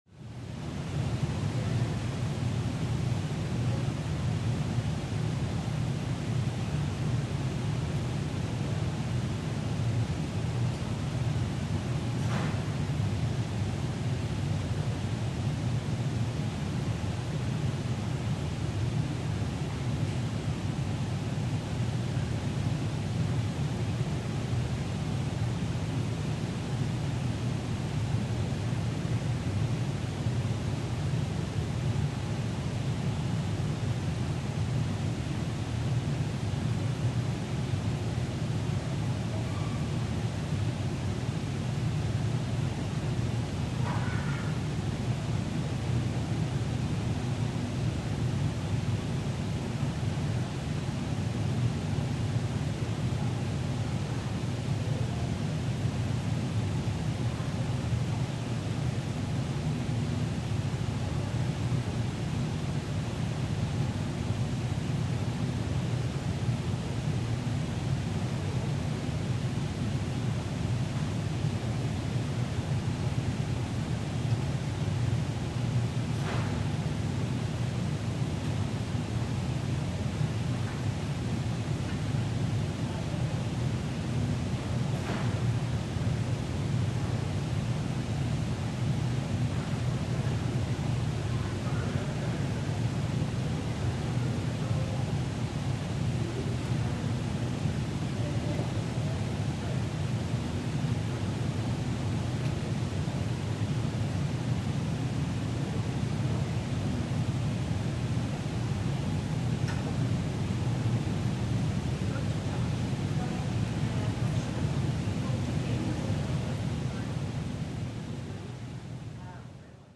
Звуки кондиционера
Фоновые звуки охлаждения: шум сплит-системы в офисе